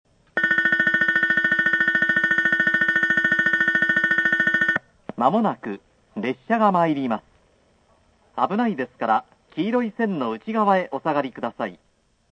スピーカー：TOA
音質：D
２番のりば 接近放送・女性 （下り・長崎方面） (63KB/12秒) 「ベル･･･」　　　まもなく列車がまいります、危ないですから黄色い線の内側へお下がりください。
福北ゆたか線標準放送です。隣の「中原」や「肥前麓」とはベルの長さが違います。また、スピーカーが異なるため、立体的に聞こえます。